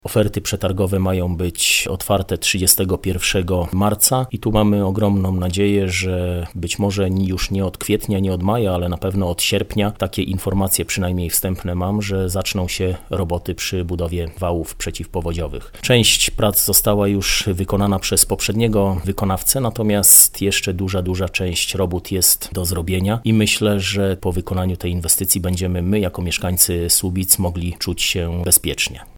– Wody Polskie rozpisały kolejny przetarg i jest ogromna szansa, że uda się go rozstrzygnąć – mówi Mariusz Olejniczak, burmistrz Słubic: